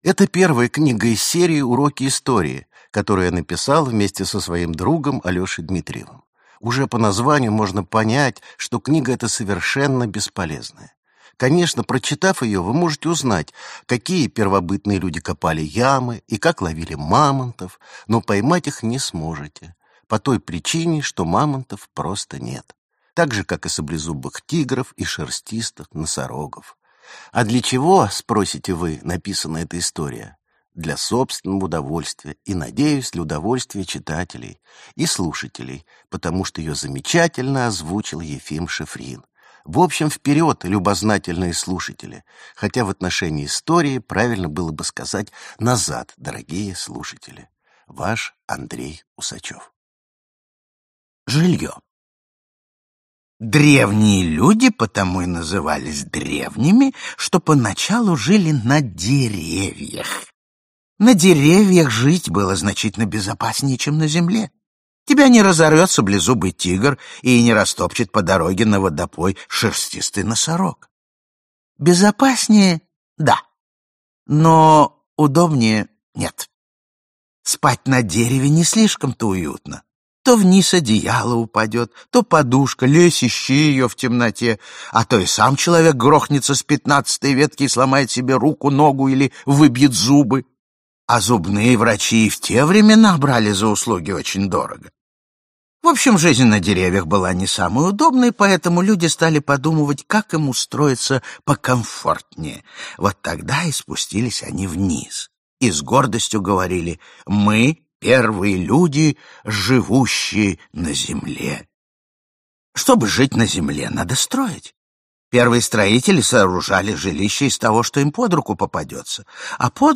Аудиокнига Как поймать мамонта. Первобытная история | Библиотека аудиокниг